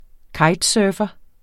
Udtale [ ˈkɑjd- ]